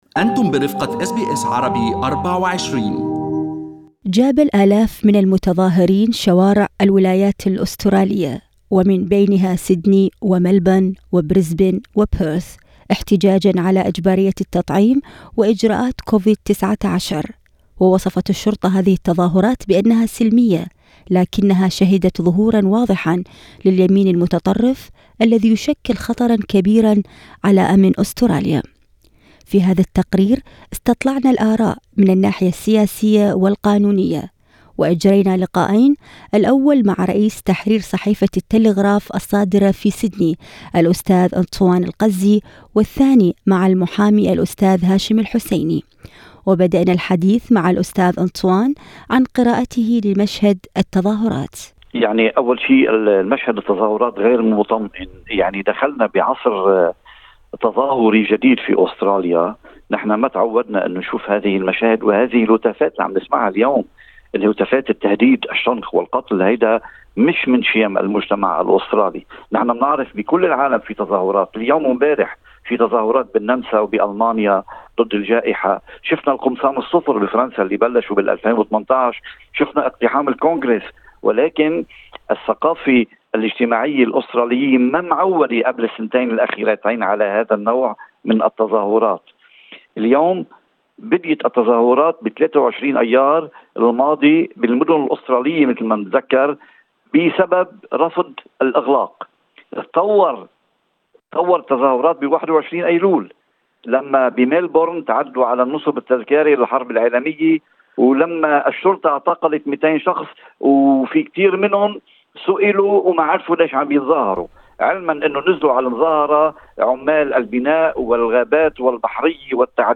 في هذا التقرير استطلعنا الآراء من الناحية السياسية والقانونية